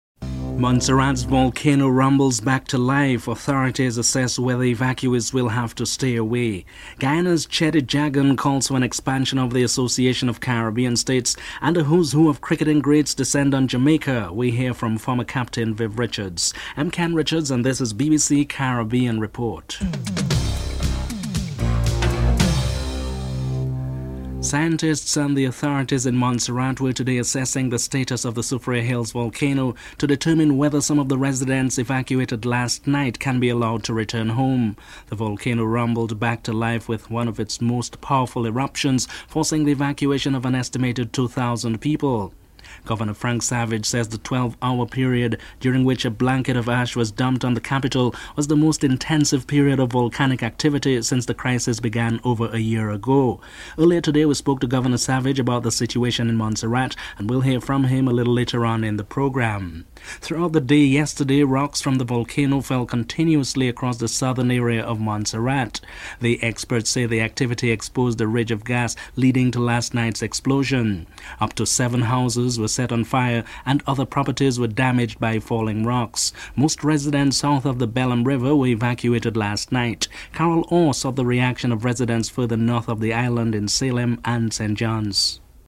1. Headlines (00:00-00:22)
2. Montserrat's volcano rumbles back to life. Governor Frank Savage and Montserratians are interviewed.